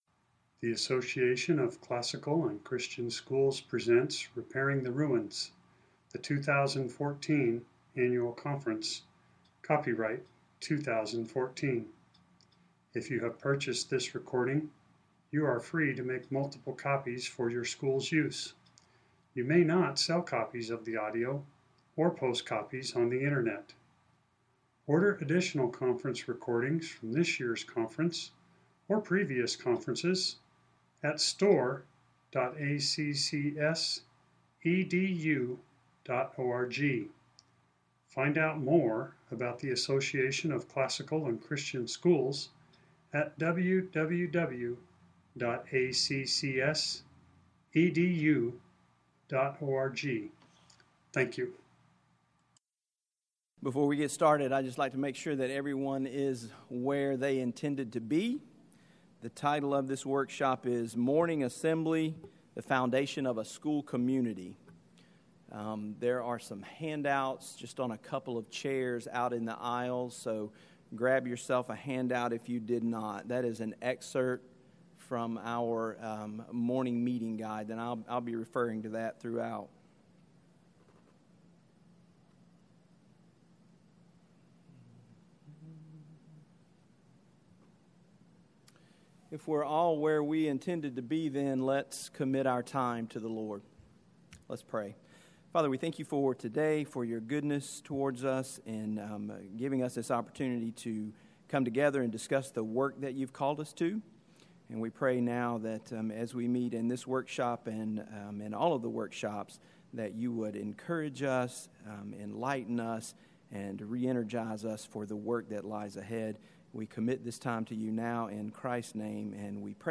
2014 Foundations Talk | 0:58:24 | All Grade Levels, Leadership & Strategic, General Classroom